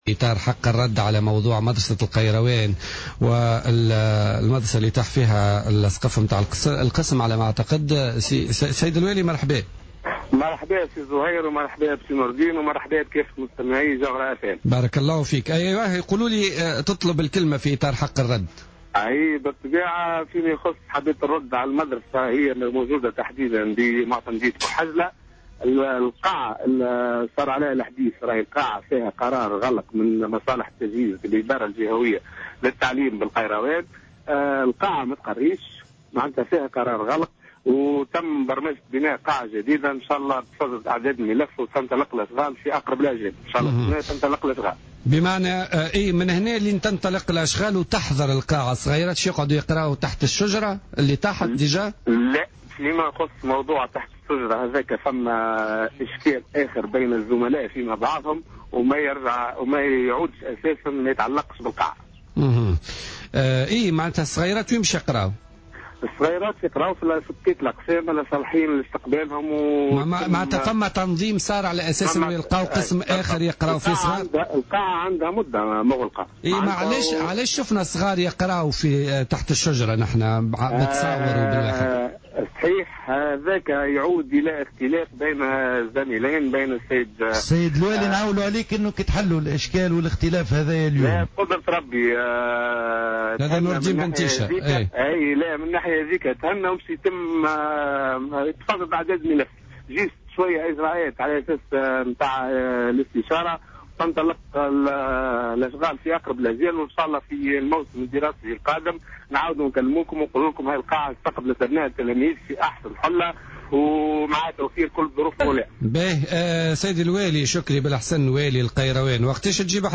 تدخل والي القيروان شكري بن حسن في مداخلة له في بوليتيكا اليوم ليطلب حق الرد بخصوص خبر سقوط سقف مدرسة بمعتمدية بوحجلة امس واضطرار المعلم لتدريس التلاميذ تحت شجرة زيتون مؤكدا أن هذه القاعة مغلقة منذ مدة طويلة وصادرة في شأنها قرار غلق .